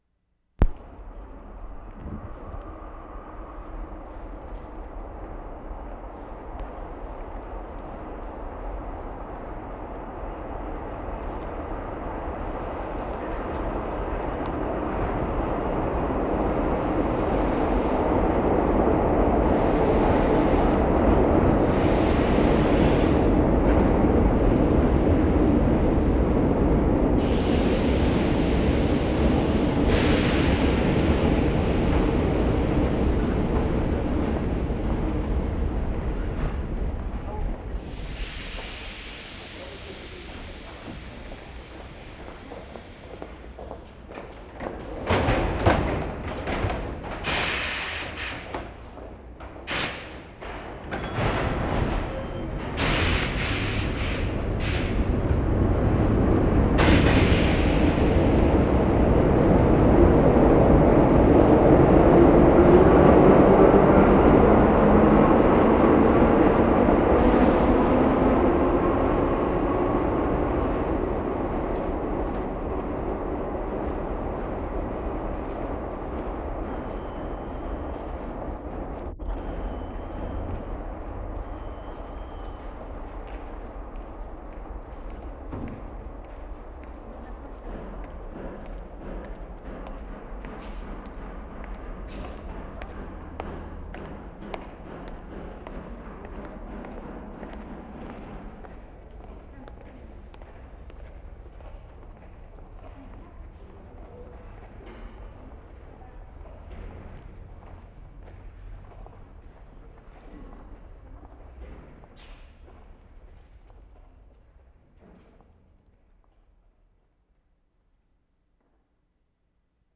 Paris, France April 8/75
2 trains arriving simultaneously.
2. Better levels in this sequence; small bell rings as train departs, afterwards reverberant footsteps; some hammering.